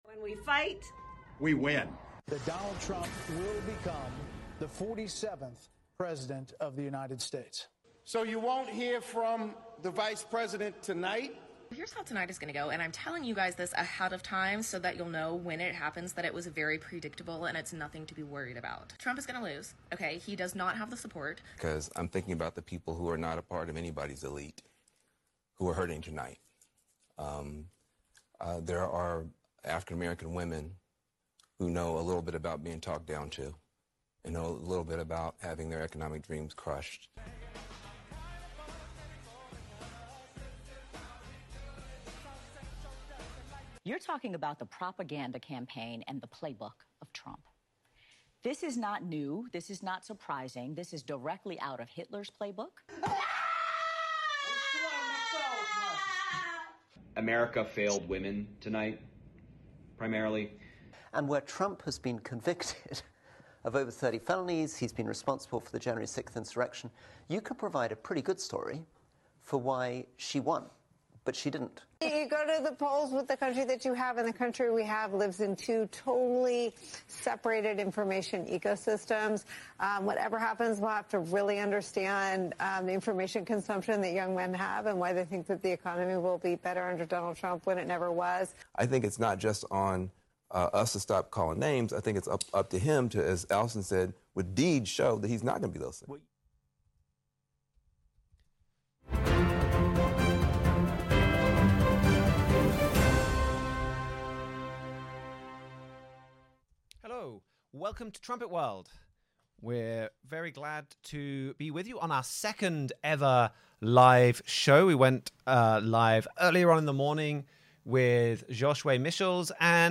Trumpet World dives into the critical news events that leap from the pages of your Bible. In today’s special live show, we’ll update you with the latest from the U.S. election, focusing on how America is reacting.
Join the discussion as Trumpet staff members compare recent news with Bible prophecy.